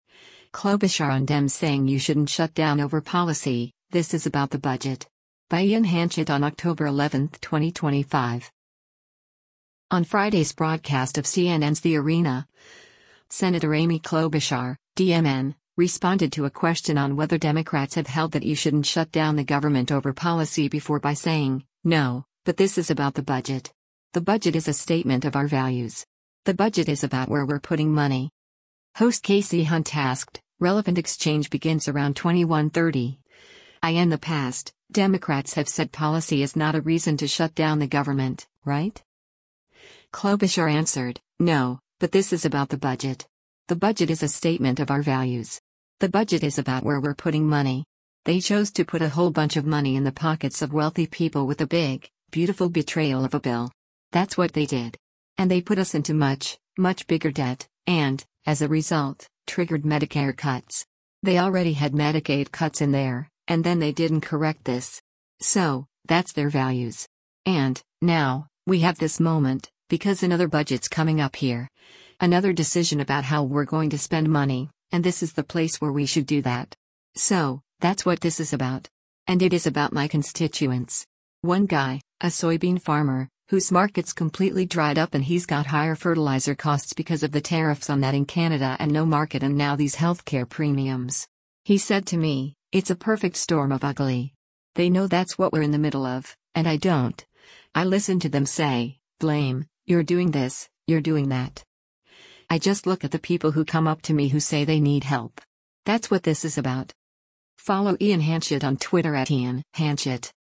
On Friday’s broadcast of CNN’s “The Arena,” Sen. Amy Klobuchar (D-MN) responded to a question on whether Democrats have held that you shouldn’t shut down the government over policy before by saying, “No, but this is about the budget. The budget is a statement of our values. The budget is about where we’re putting money.”